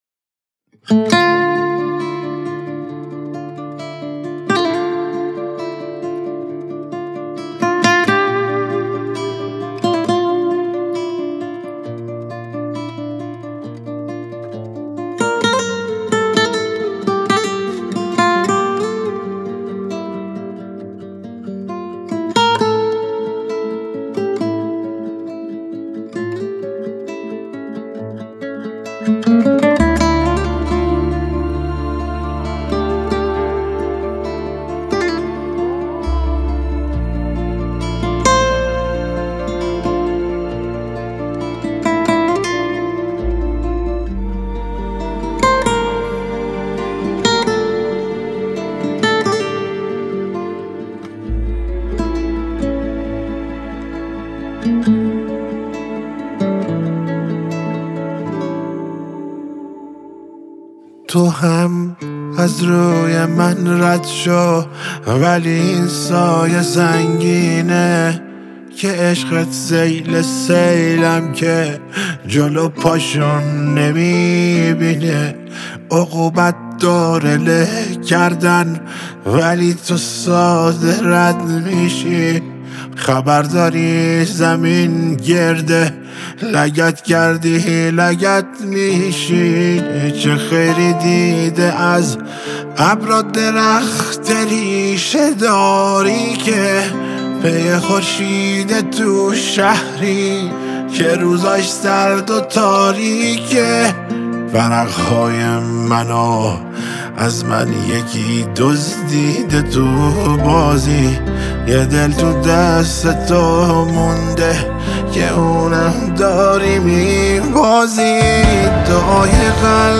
گیتار
دودوک